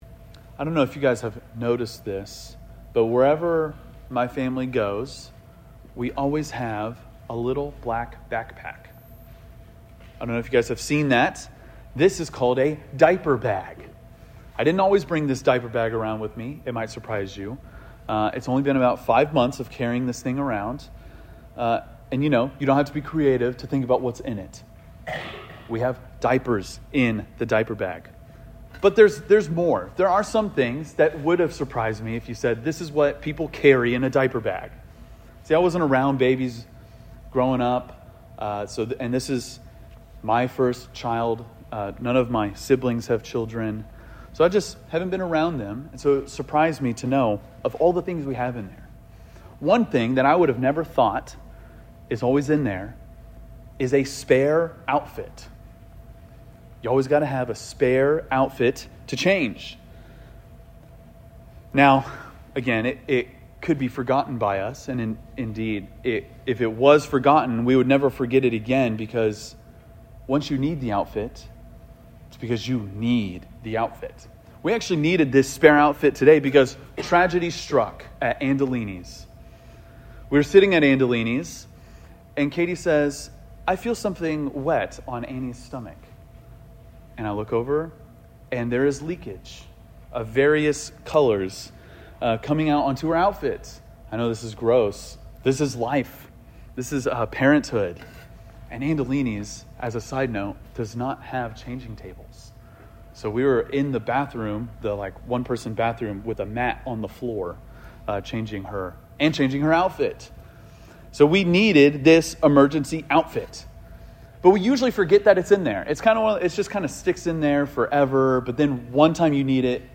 preaches on this question by looking at the story of Job, Lazarus, and Matthew 11:25-30.